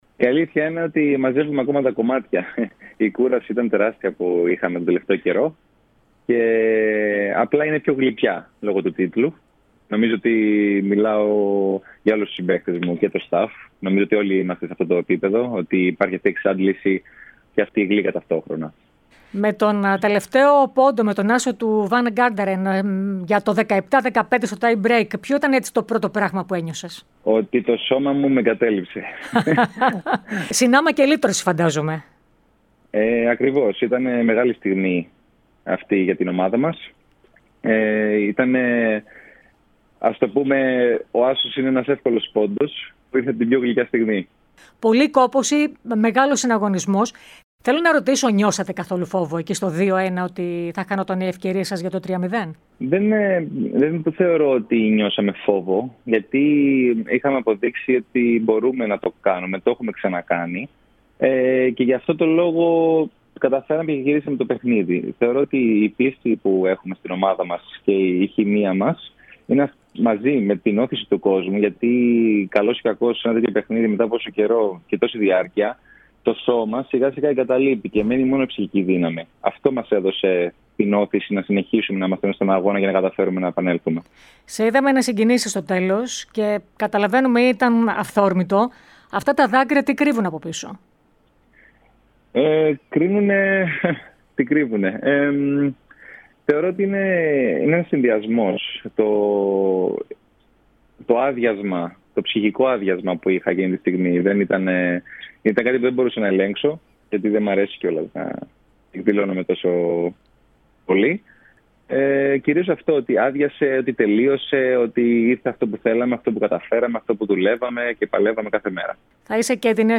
Η εμβληματική φιγούρα του Παναθηναϊκού, Θανάσης Πρωτοψάλτης, φιλοξενήθηκε στον αέρα της ΕΡΑ ΣΠΟΡ και στην εκπομπή "Citius, Altius, Fortius" μία ημέρα μετά την κατάκτηση του πρωταθλήματος από τους "πράσινους", οι οποίοι επέστρεψαν στην κορυφή της Volley League μετά από τρία χρόνια.